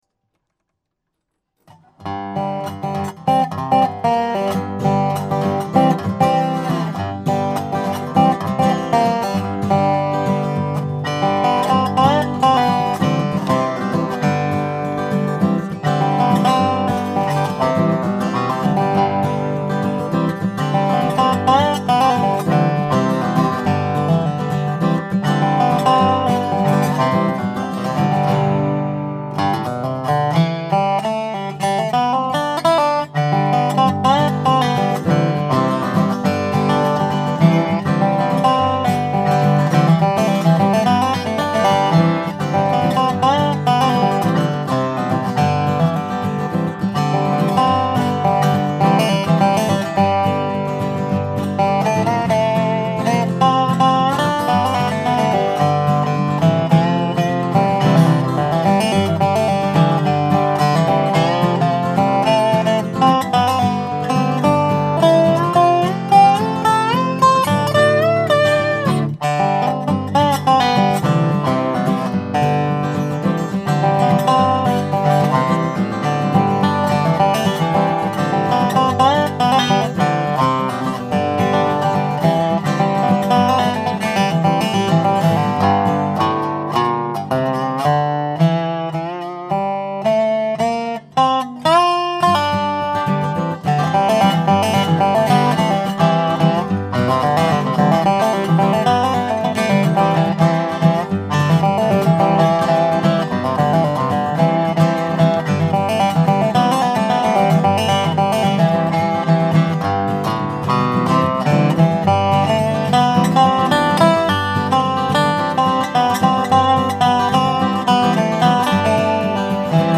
Dobro, Guitar